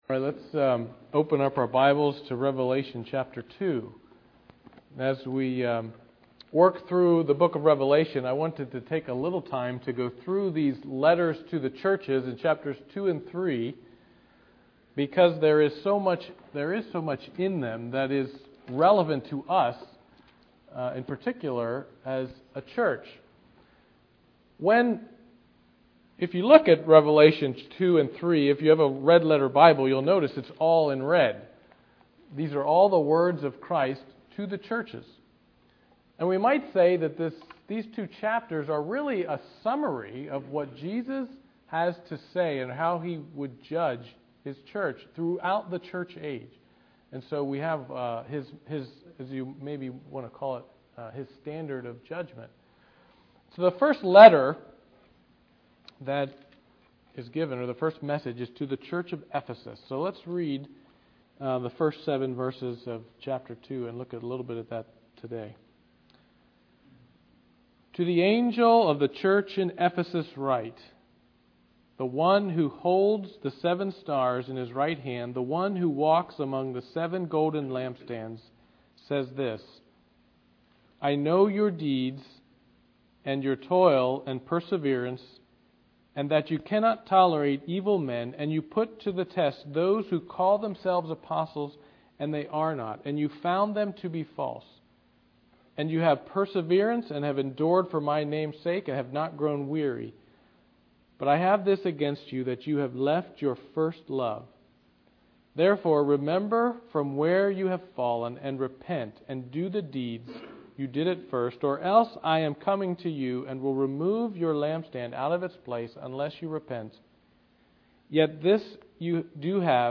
Passage: Revelation 2 Service Type: Sunday Evening Worship